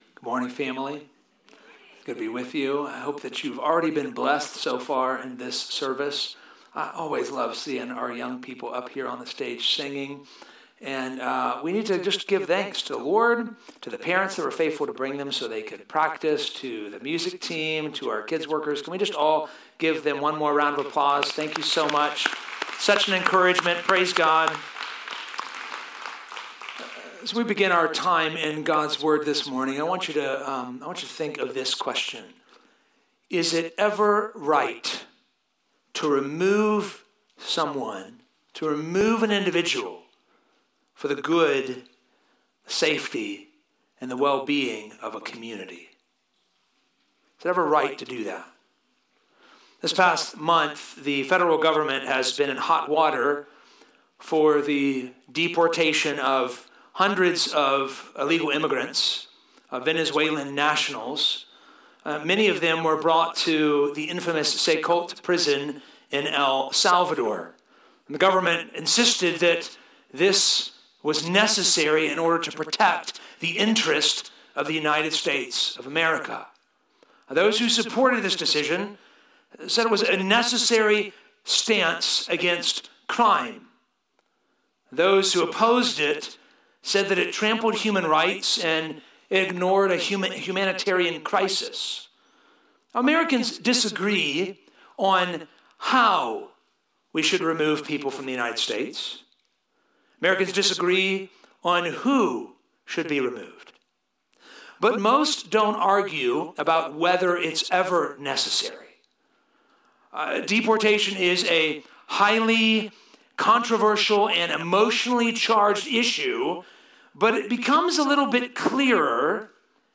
This Week's Sermon